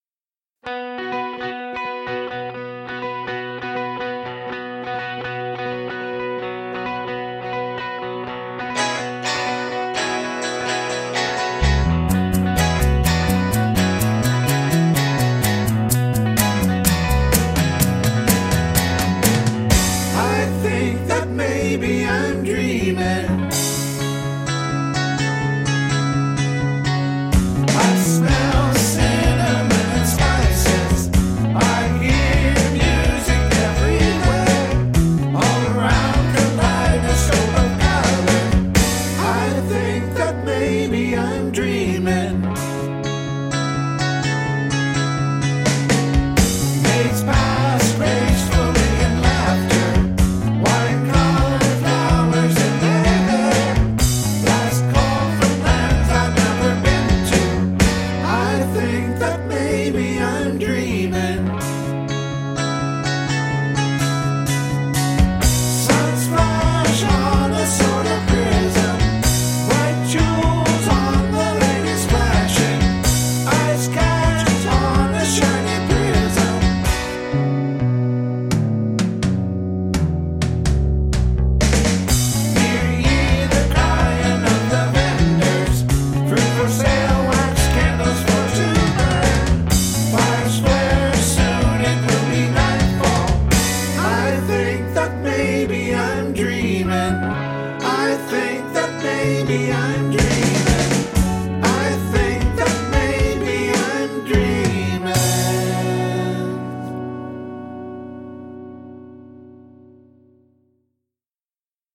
- Classic Rock Covers -
All these songs were recorded in my basement
These are unmastered headphone mixes, and